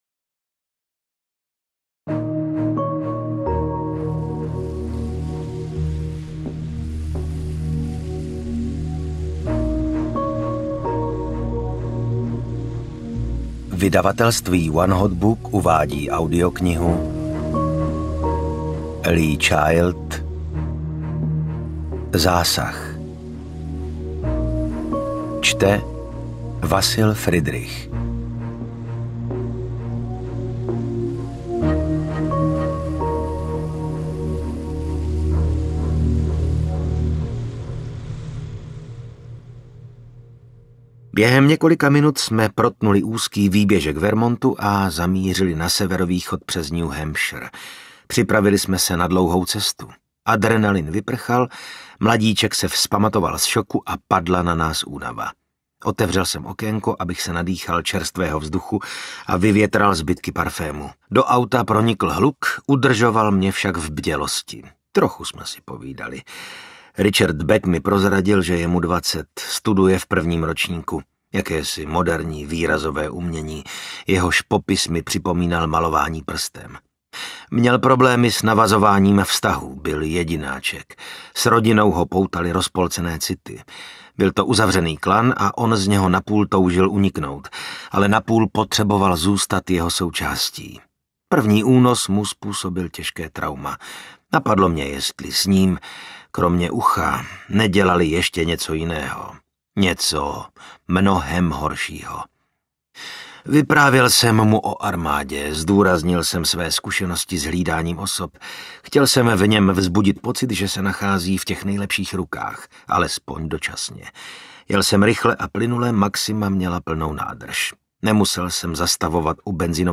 Jack Reacher: Zásah audiokniha
Ukázka z knihy